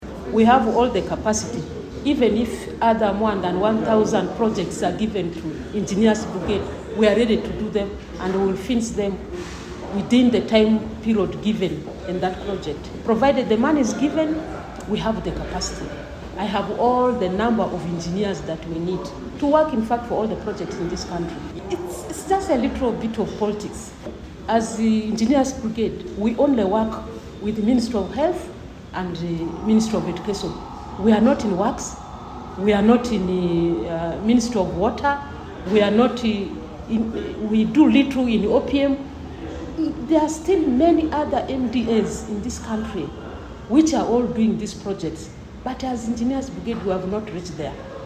AUDIO: Minister Huda Oleru